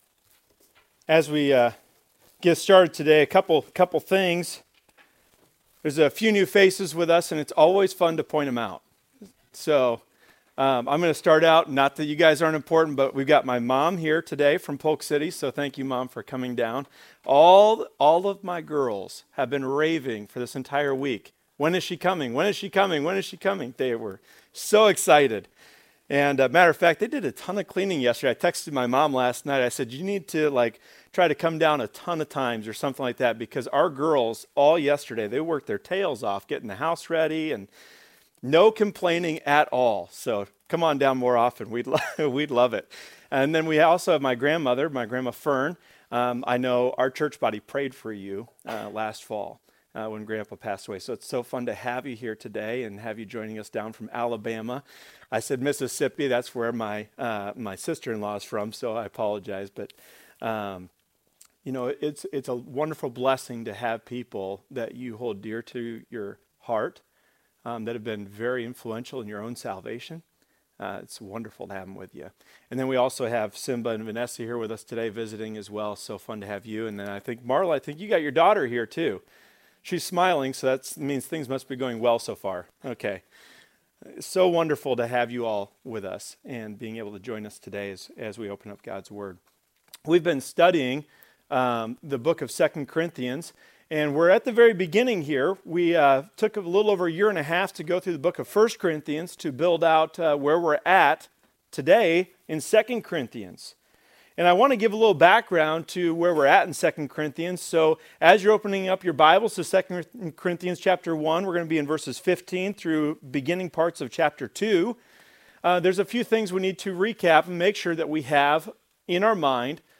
Services